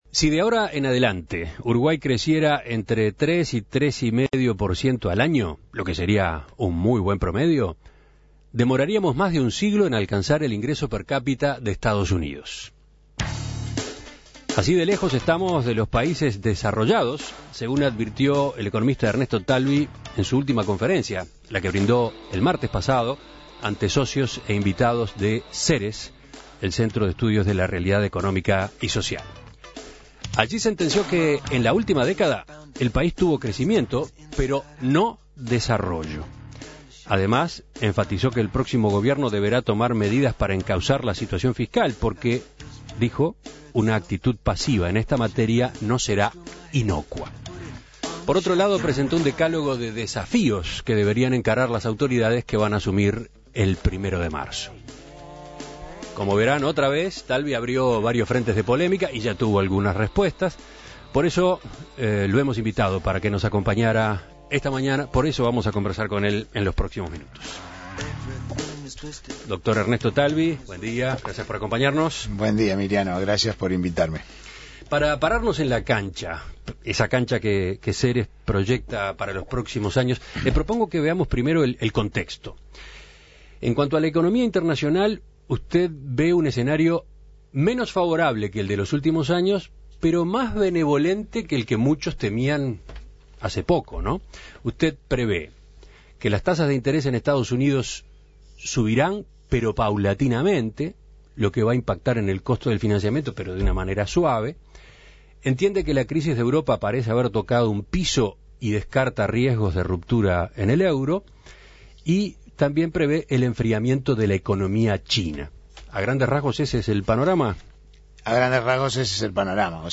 También adelantó que el contexto internacional no va a ser tan favorable como lo ha venido siendo en los últimos años. En Perspectiva entrevistó a Talvi para profundizar en las conclusiones principales de su disertación.